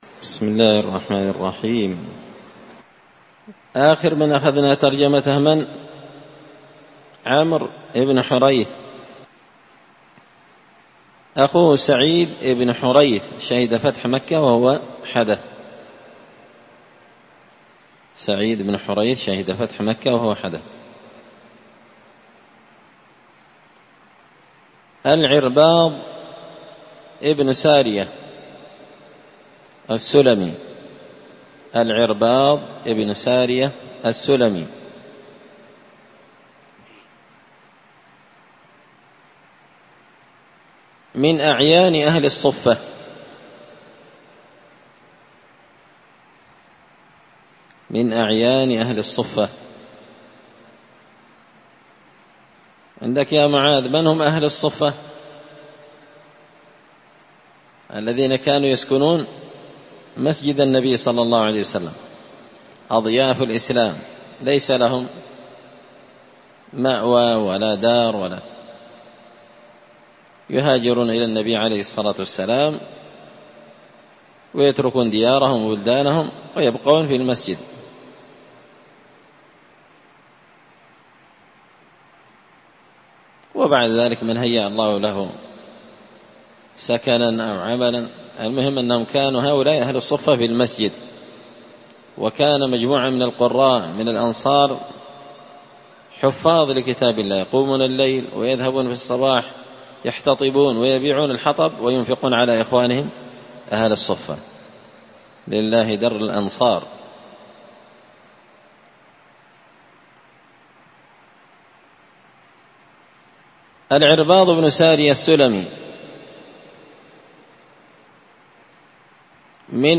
الدرس 218 سعيد ابن حريث
قراءة تراجم من تهذيب سير أعلام النبلاء